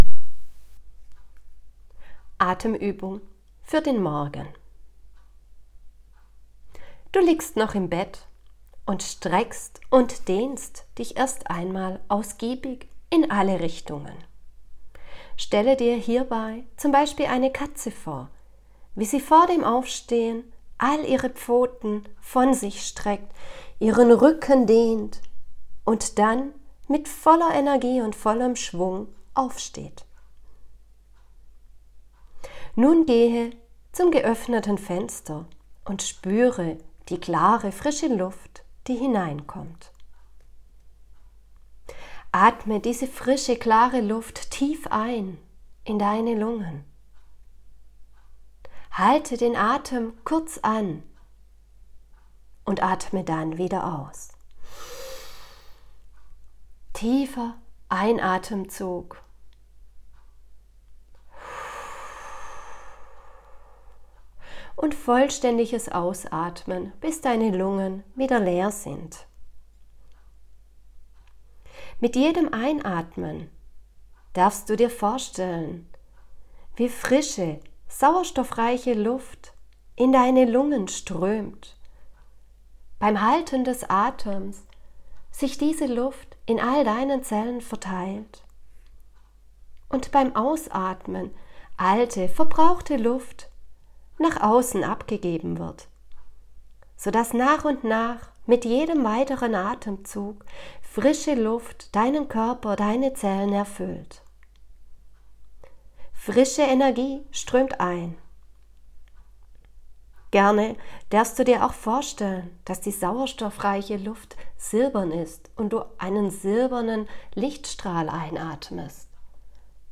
Morgenübung
atemuebung-am-morgen.m4a